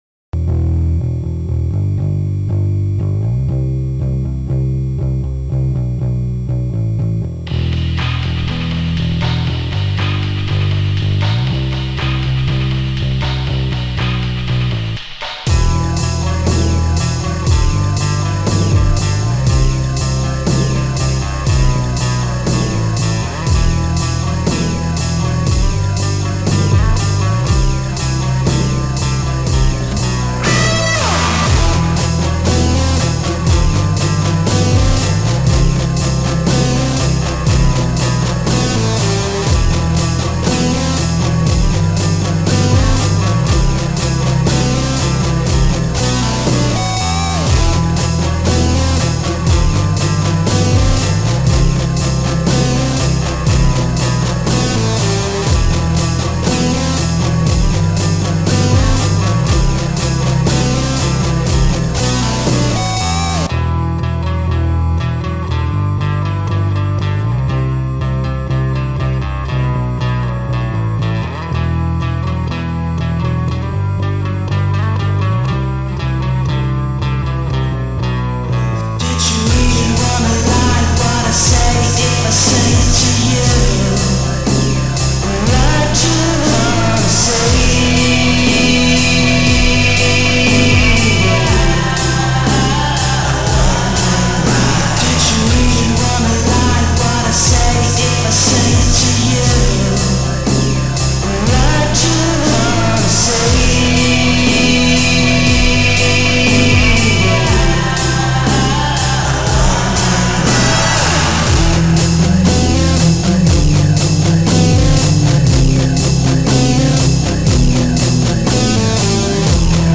Aber um die Magix Zeit und die Auswirkungen des fabelhaften bunten Raumes besser darstellen zu können.....es klang damals so Beispiel 1 Dein Browser kann diesen Sound nicht abspielen.